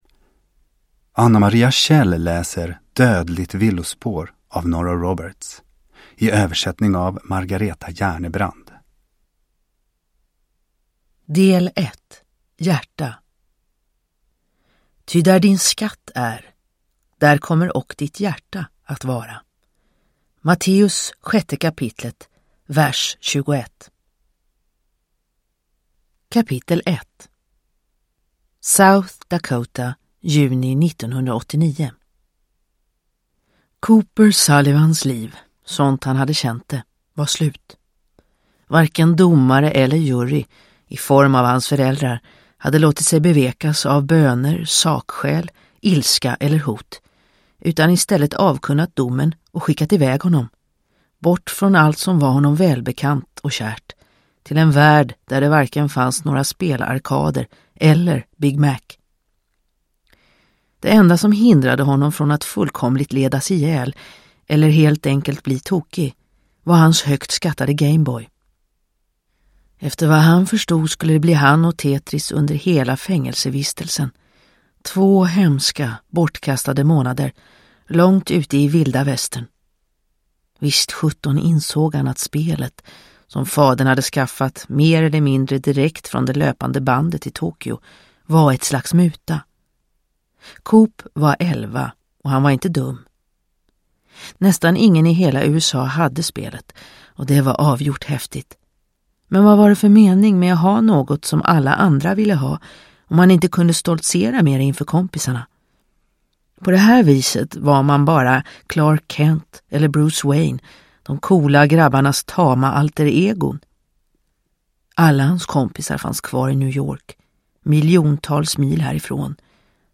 Dödligt villospår – Ljudbok – Laddas ner